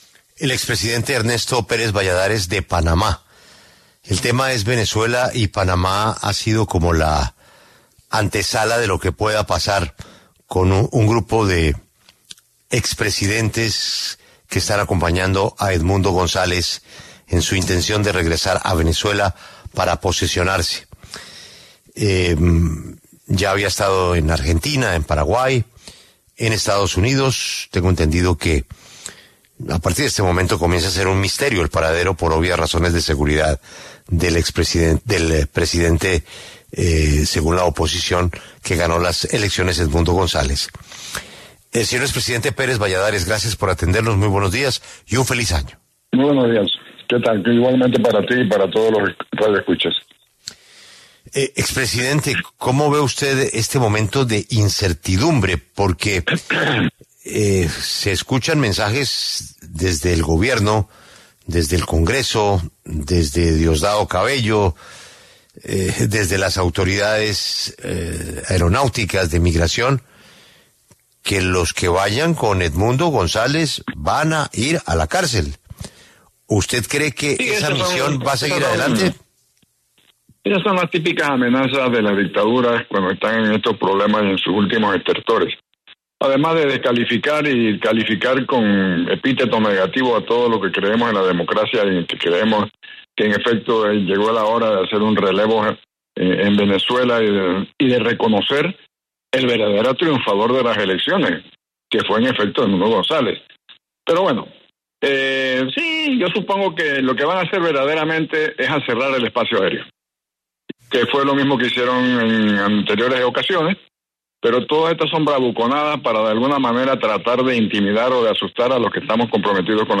En conversación con La W, Ernesto Pérez Balladares, expresidente de Panamá, se refirió a las declaraciones de Donald Trump, mandatario electo de Estados Unidos, sobre recuperar el Canal de Panamá.